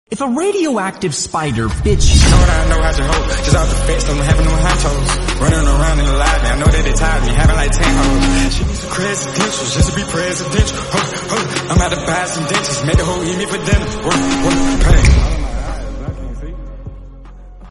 (reverbed)